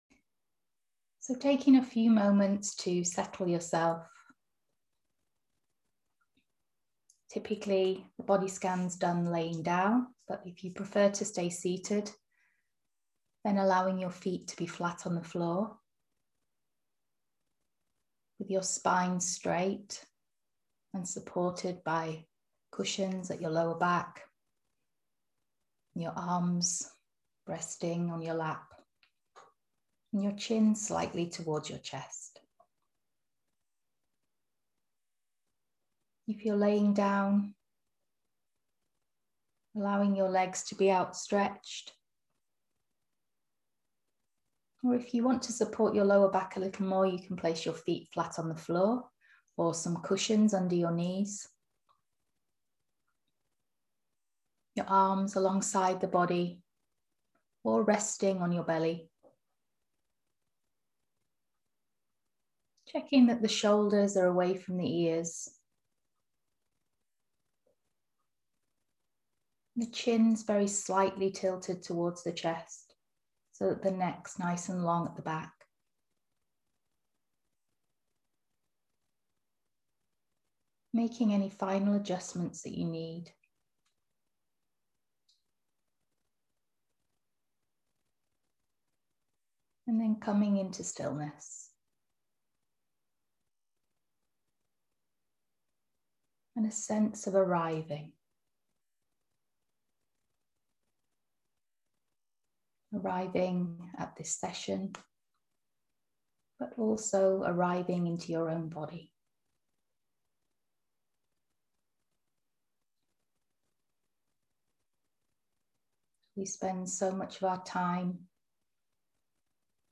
Body Scan Meditation | Kindfulness Effect